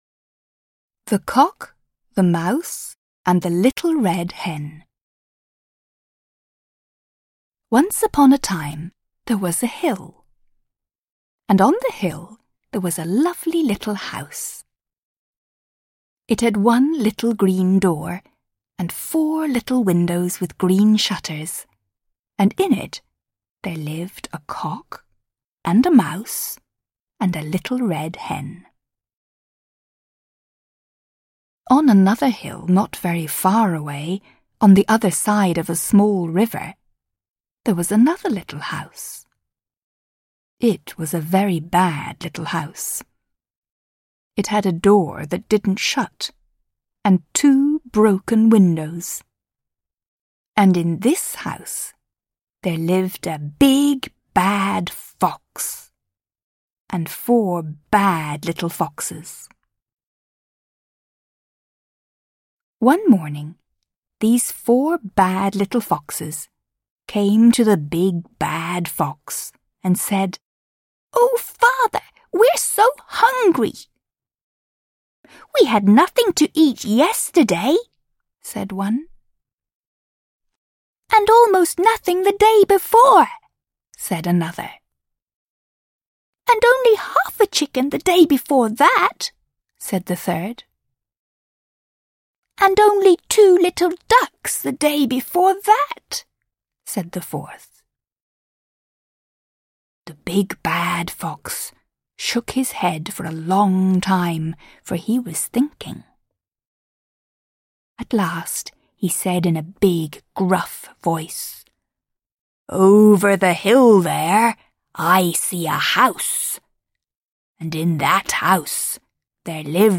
Аудиокнига English Fairy Tales | Библиотека аудиокниг